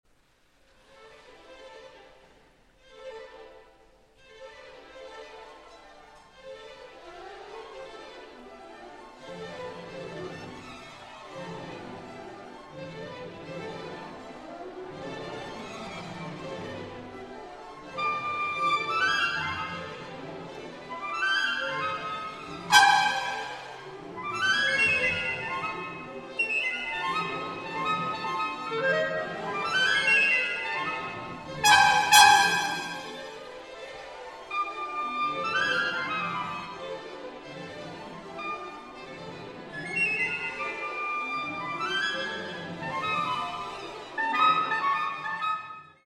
First Recordings, Live